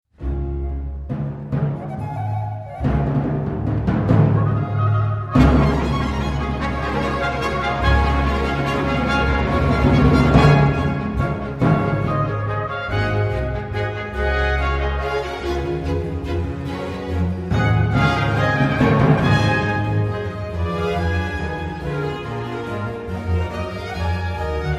BACH, oratorio de noel bwv248, 01 choeur - CAMPIN (Robert), Nativite.mp3